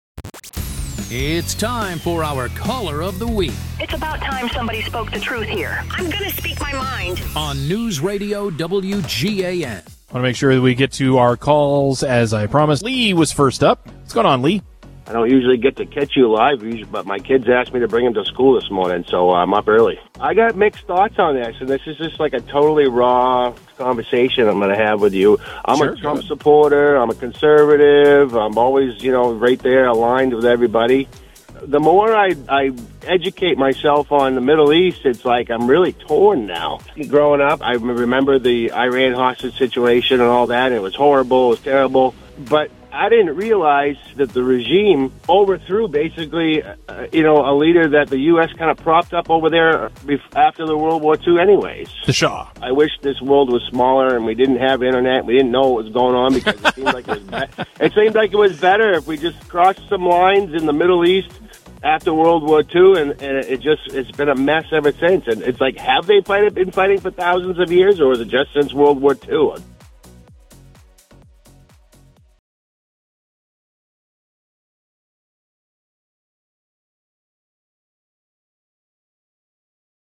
one person calling into the Morning News, who offers an interesting take, a great question or otherwise bring something to the discussion that is missing.